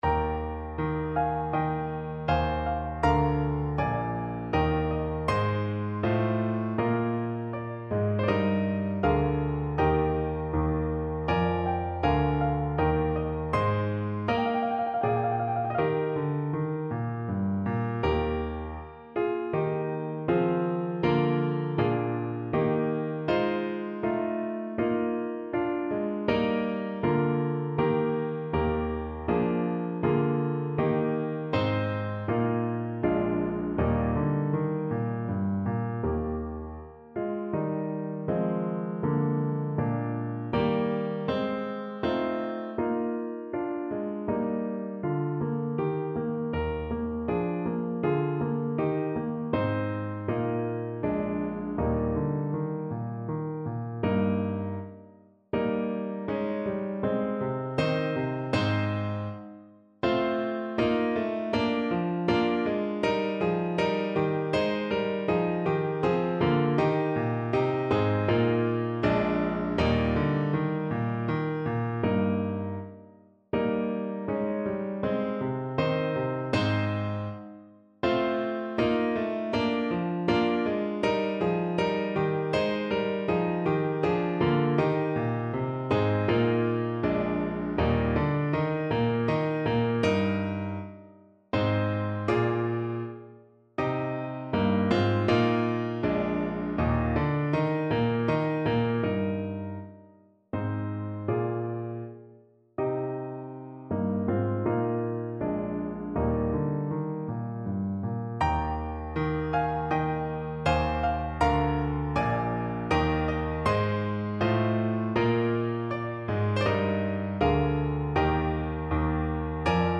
3/4 (View more 3/4 Music)
Andante =80
Trumpet  (View more Intermediate Trumpet Music)
Classical (View more Classical Trumpet Music)